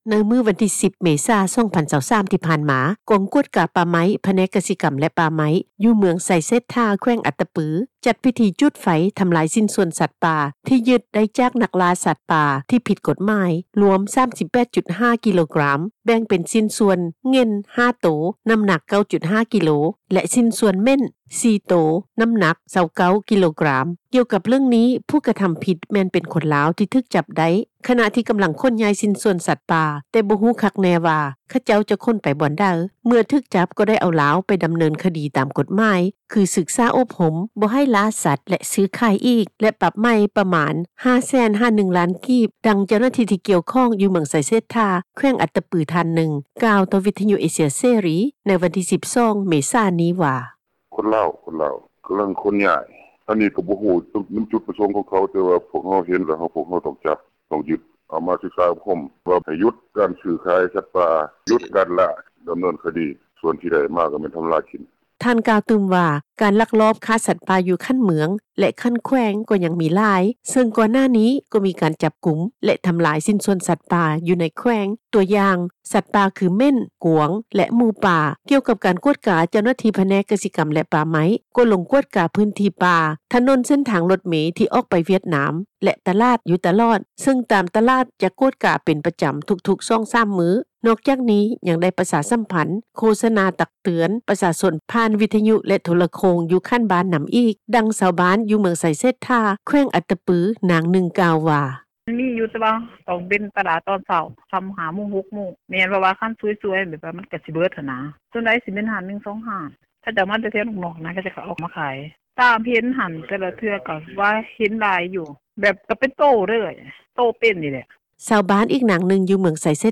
ດັ່ງຊາວບ້ານ ຢູ່ເມືອງໄຊເສດຖາ ແຂວງອັດຕະປື ນາງນຶ່ງ ກ່າວວ່າ:
ດັ່ງ ພໍ່ຄ້າຮັບຊື້ສັດປ່າ ຢູ່ລາວ ທ່ານນຶ່ງ ກ່າວວ່າ: